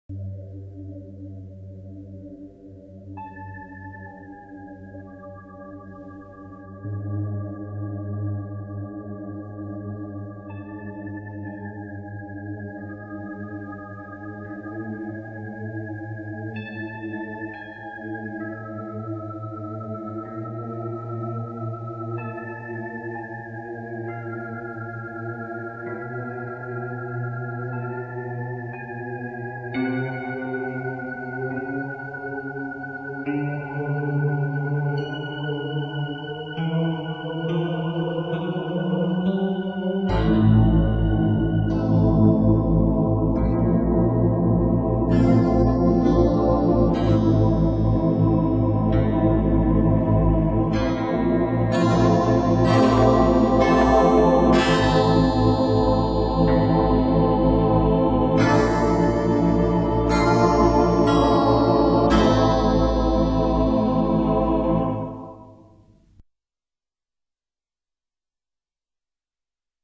Soundtracks composed for a romantic trilogy.